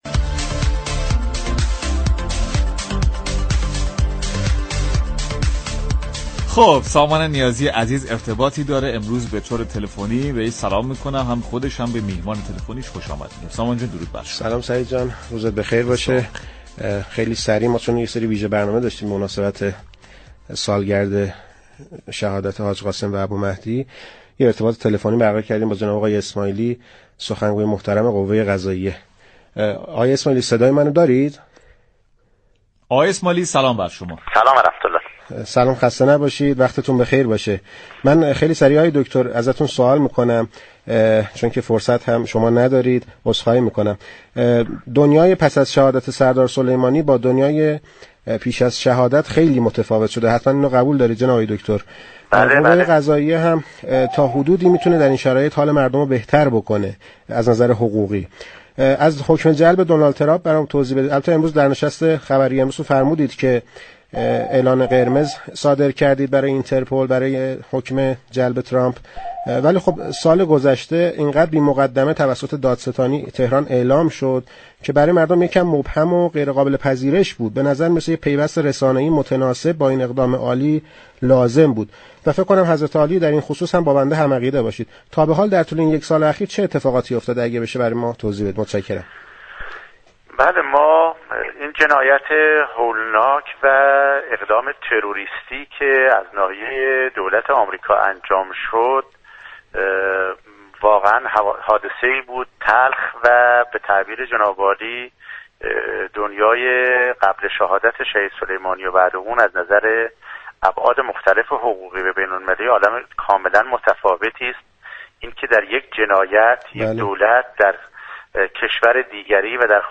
غلامحسین اسماعیلی در گفتگو با این برنامه با اشاره به جنایت هولناك و اقدام تروریستی شهادت سپهبد سلیمانی به دستور مستقیم دونالد ترامپ گفت: دنیای پیش از شهادت و بعد از شهادت سردار سلیمانی، دو دونیای بسیار متفاوت است.